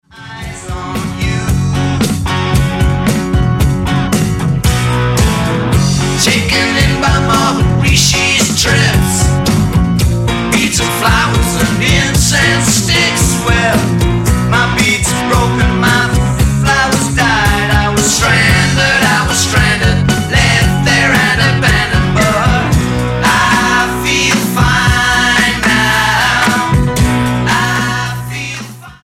STYLE: Jesus Music
mid '70s pop rock